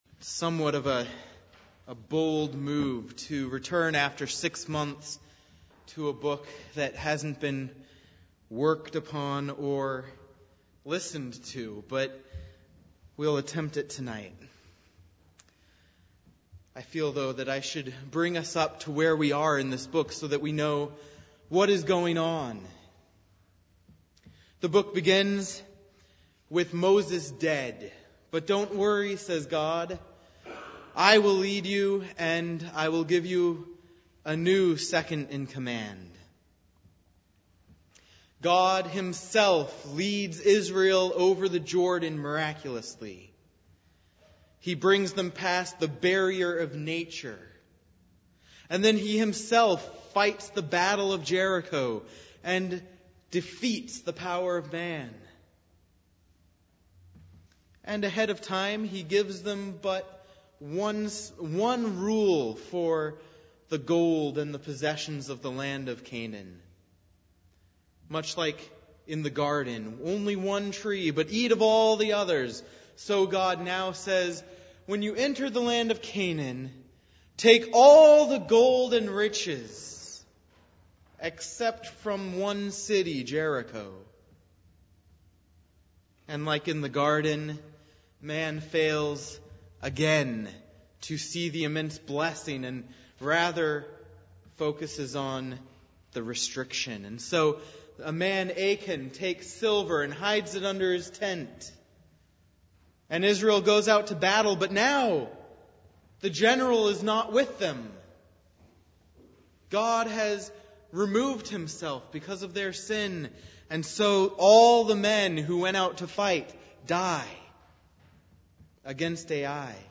John 4:7-26 Service Type: Sunday Evening Sermon on Joshua 8:24-35 « The Annointed King’s Beatitude 2.